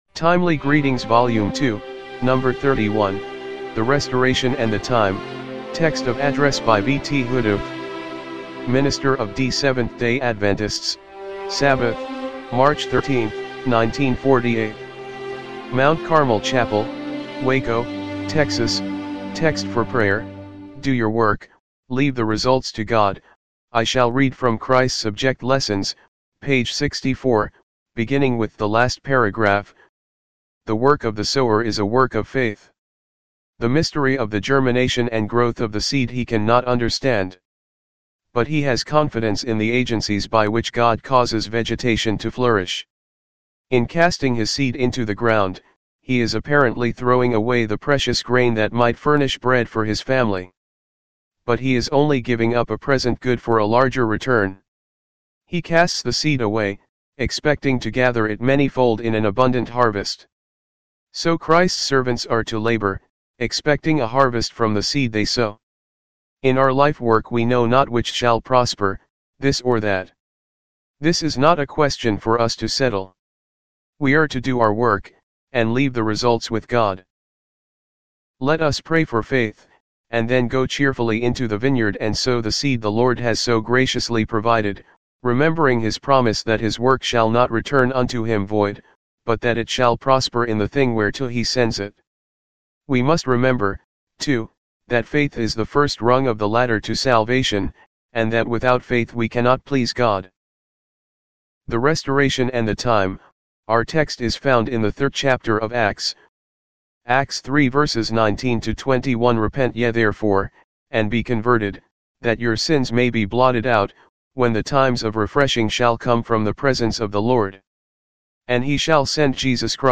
timely-greetings-volume-2-no.-31-mono-mp3.mp3